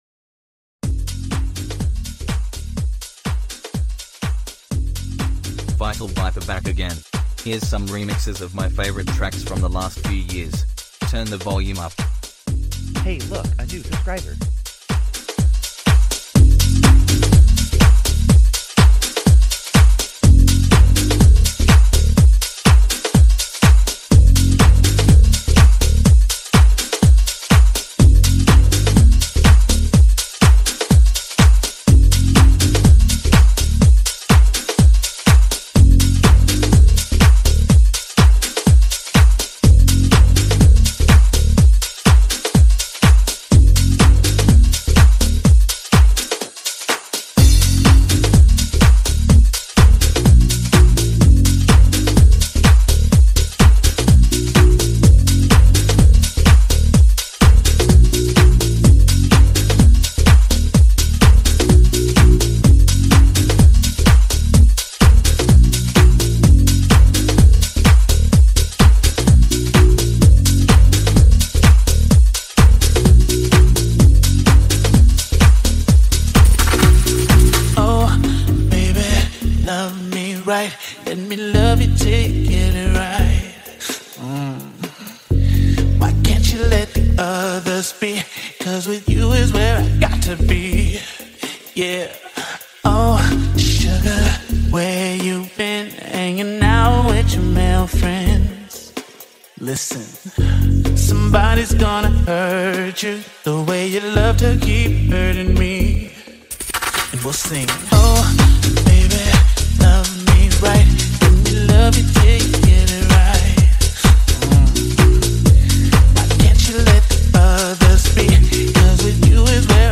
fusing house beats with electric vibes.